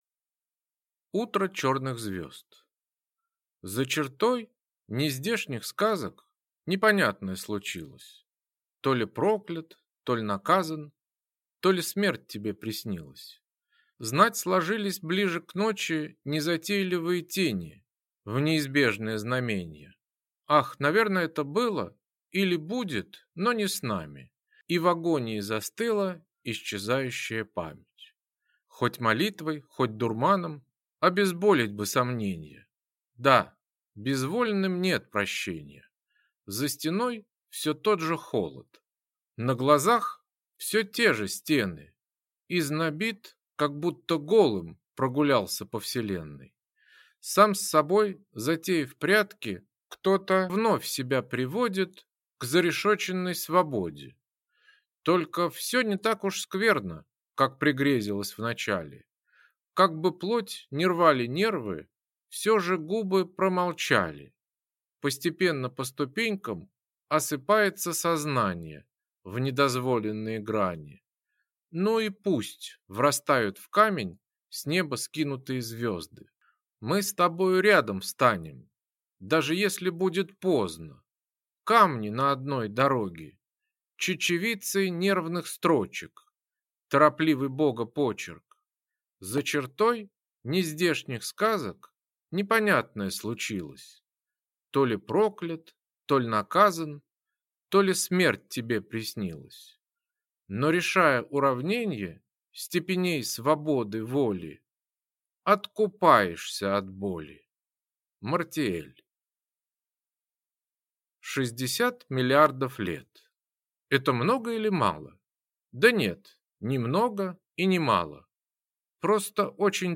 Аудиокнига Утро черных звезд | Библиотека аудиокниг